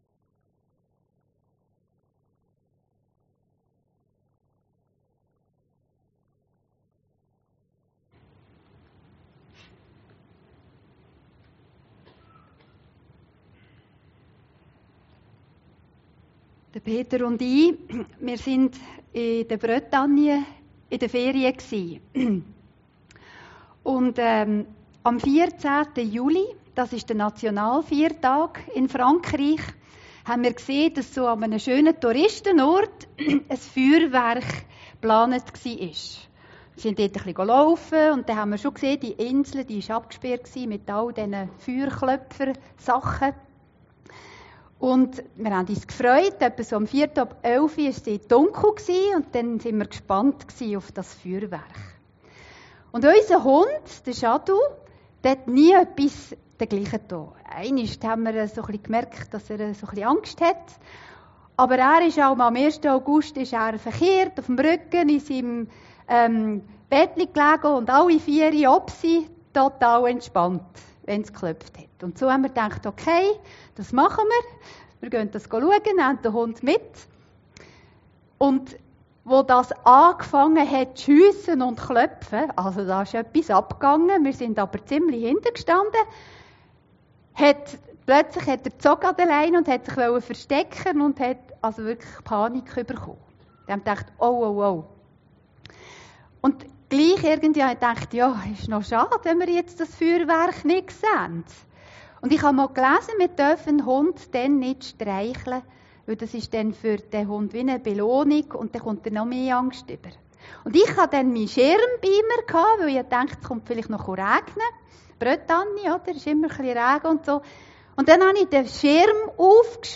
Predigten Heilsarmee Aargau Süd – Geborgen unter dem Schutz Gottes